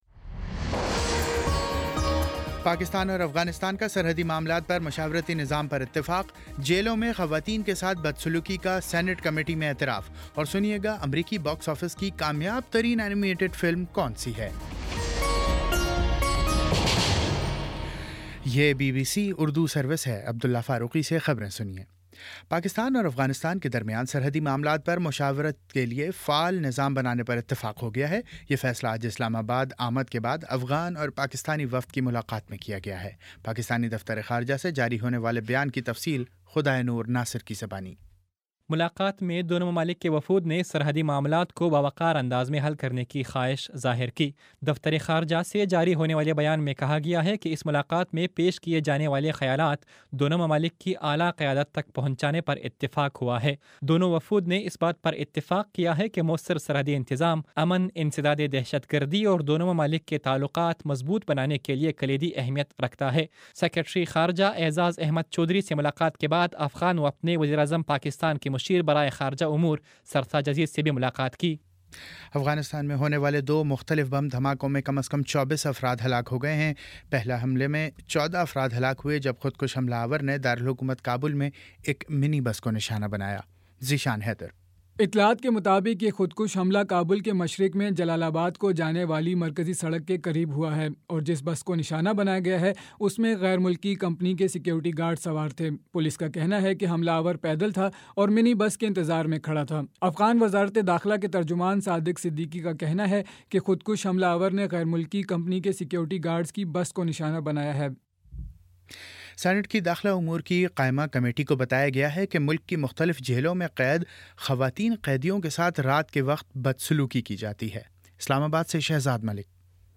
جون 20 : شام چھ بجے کا نیوز بُلیٹن